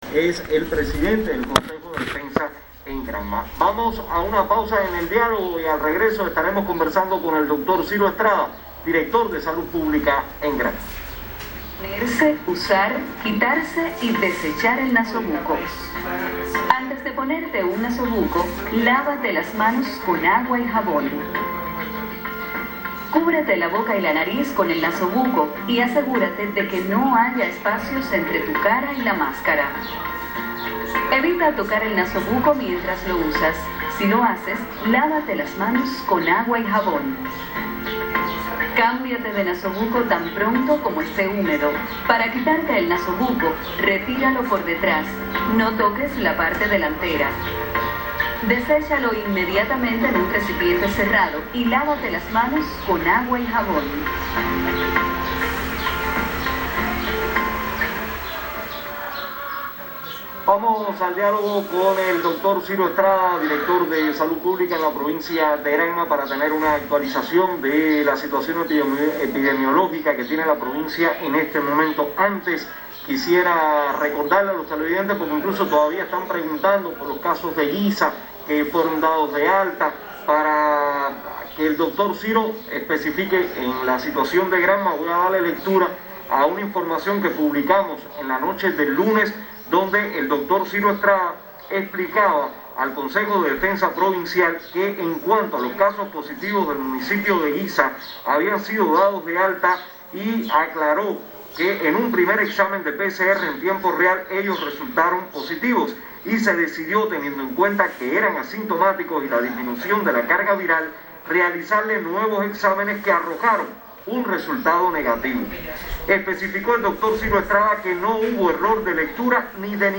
Así lo aseguró al mediodía de hoy el Doctor Ciro Estrada director de Salud Pública en Granma en el programa televisivo Perspectivas, y otorgó una gran importancia a la actuación y disciplina de los granmenses, con el uso del nasobuco, mantener el distanciamiento y el aislamiento social.